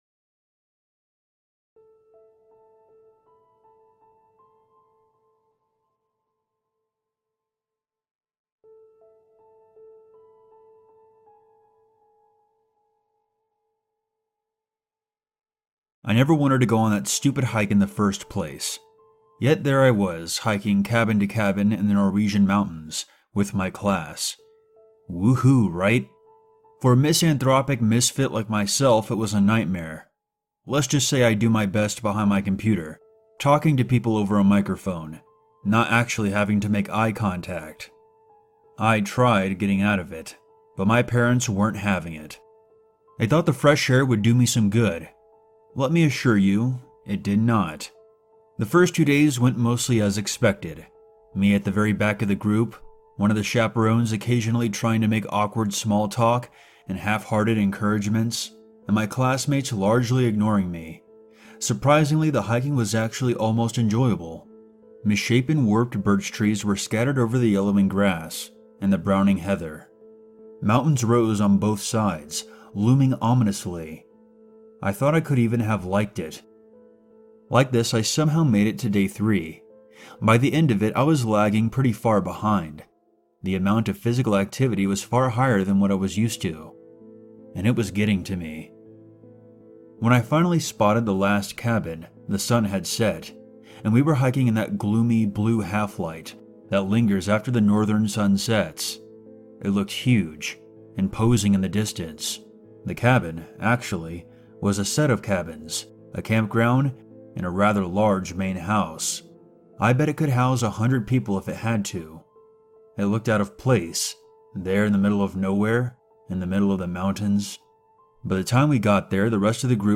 All advertisements are placed exclusively at the beginning of each episode, ensuring complete immersion in our horror stories without interruptions. Experience uninterrupted psychological journeys from start to finish with zero advertising breaks. When darkness awakens and paranormal activity echoes through abandoned corridors, Horror Nightmares Stories transforms ordinary nights into supernatural horror experiences that haunt your dreams.